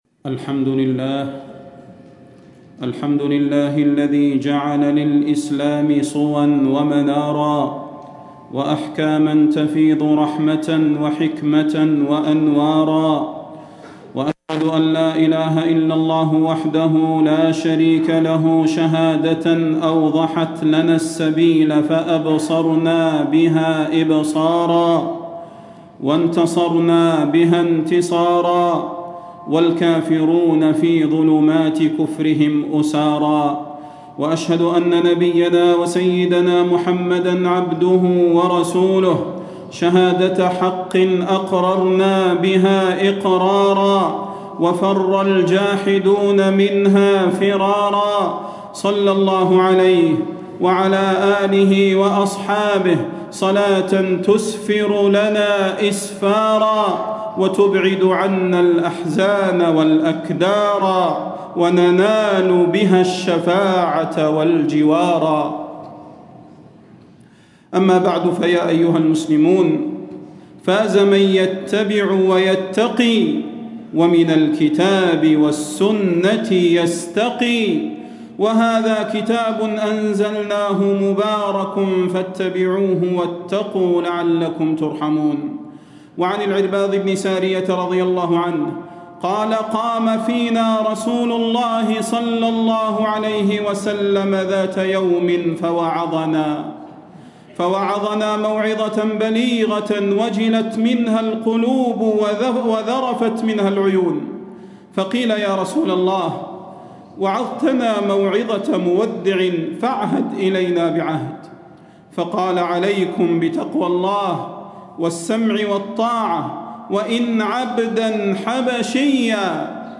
تاريخ النشر ١٨ شعبان ١٤٣٦ هـ المكان: المسجد النبوي الشيخ: فضيلة الشيخ د. صلاح بن محمد البدير فضيلة الشيخ د. صلاح بن محمد البدير ولتستبين سبيل المجرمين